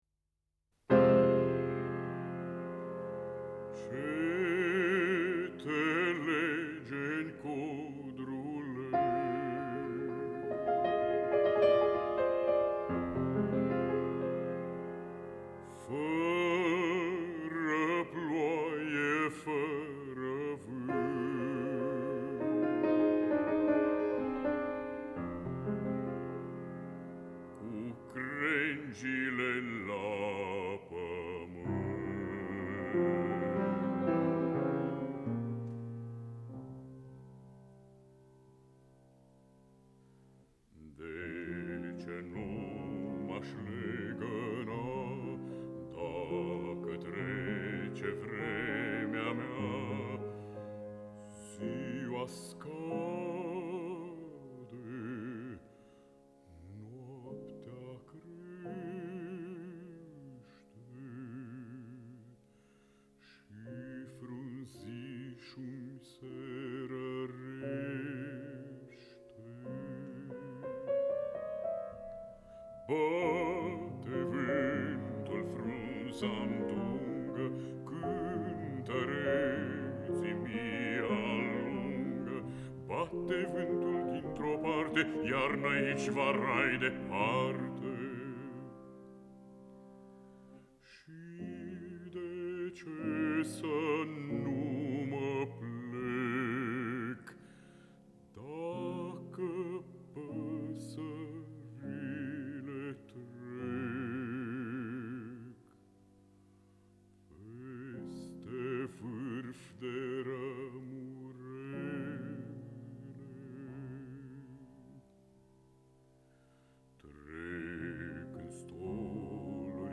Lied
basul
acompaniat la pian
Înregistrare pe banda magnetica